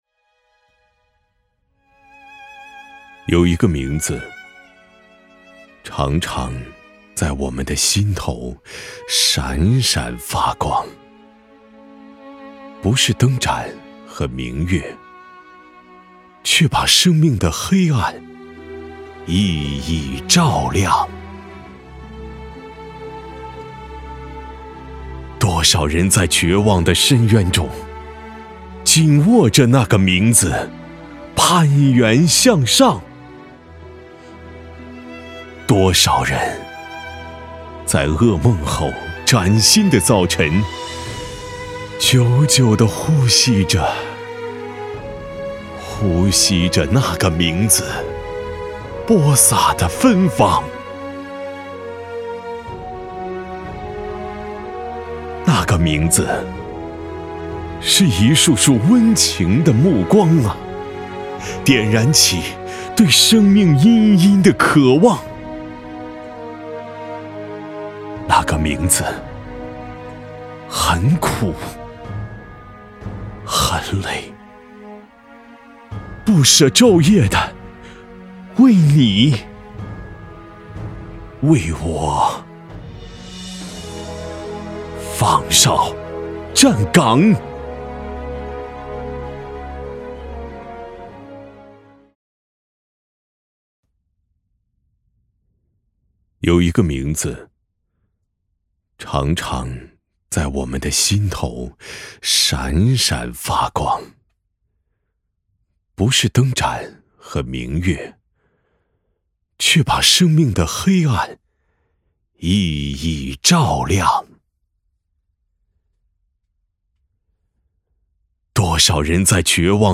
深度配音散文诗歌朗诵配音样音页面提供配音样音免费在线试听服务，可根据样音试听选择需要的配音来进行试音。
男198--朗诵-护士节-情感饱满.mp3